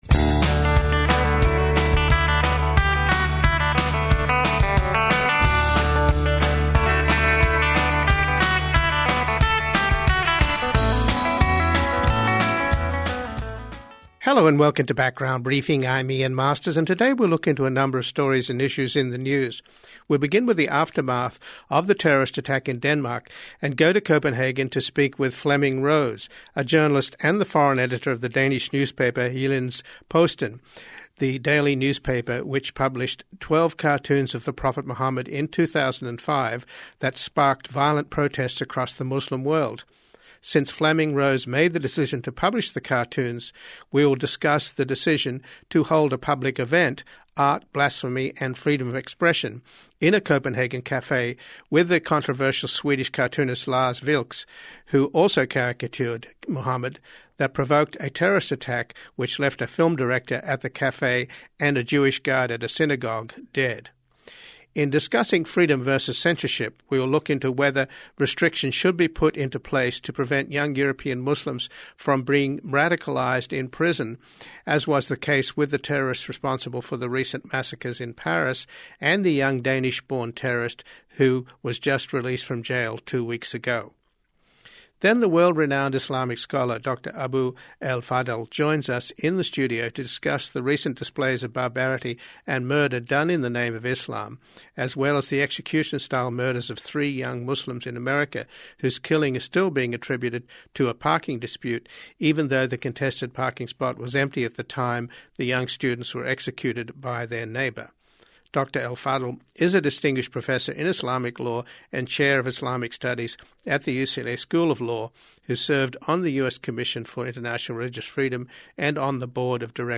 Part 2 Then the world-renowned Islamic scholar Dr. Abou El Fadl joins us in the studio to discuss the recent displays of barbarity and murder done in the name of Islam, as well as the execution-style murders of three young Muslims in America whose killing is still being attributed to a parking dispute even though the contested parking spot was empty at the time the young students were executed by their neighbor.